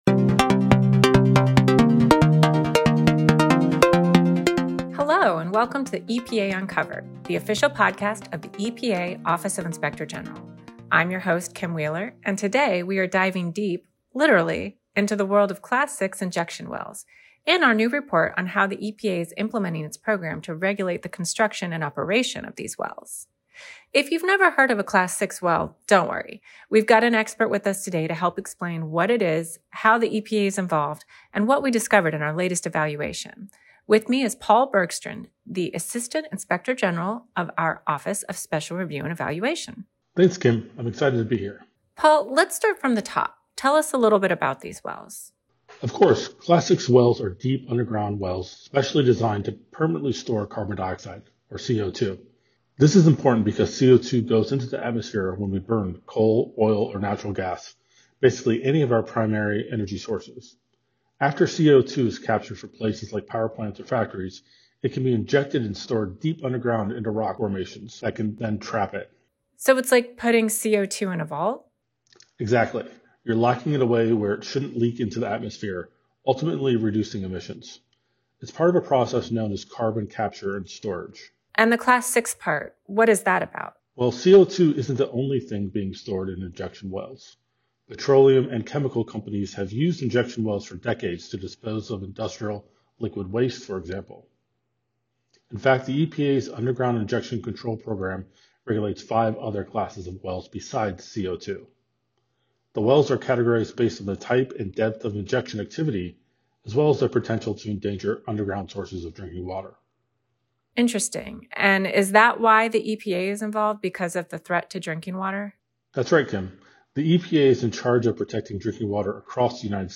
Interview with Paul Bergstrand, Assistant Inspector General for Office of Special Review and Evaluation